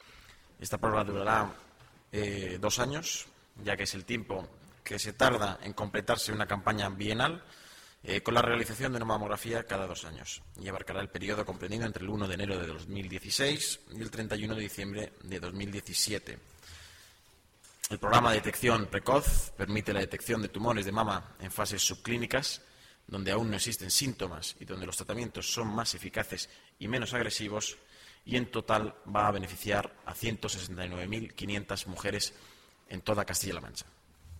Así lo ha anunciado hoy el portavoz del Ejecutivo autonómico, Nacho Hernando, en la rueda de prensa que ha ofrecido en el Palacio de Fuensalida para informar de los acuerdos del Consejo de Gobierno celebrado ayer martes en Azuqueca de Henares.